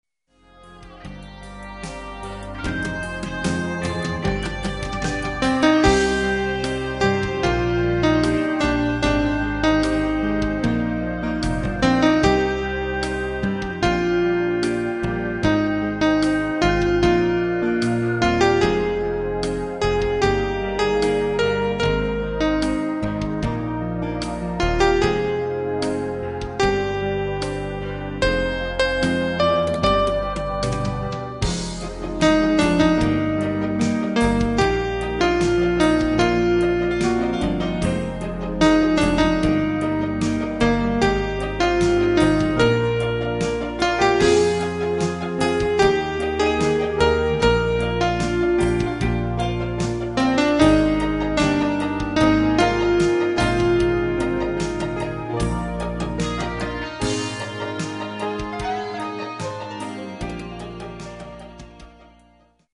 (музыкальная тема) 20 - 25 февраля 2001г.